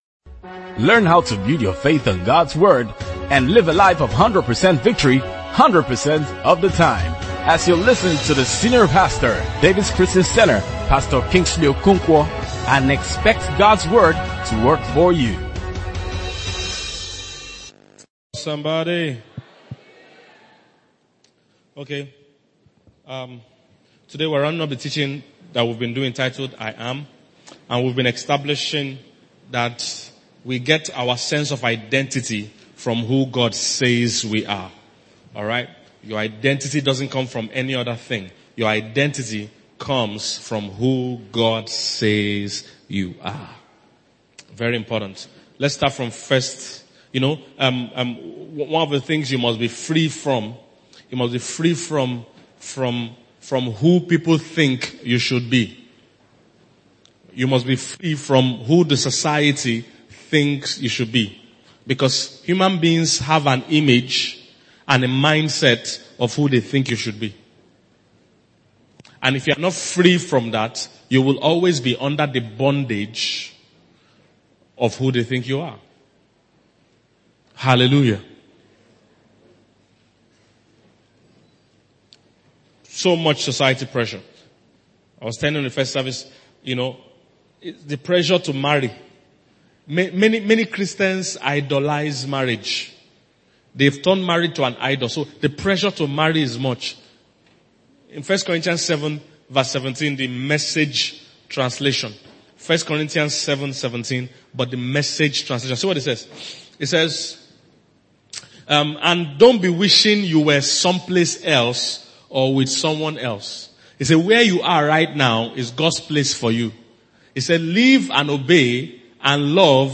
Inspirational & Relationship Messages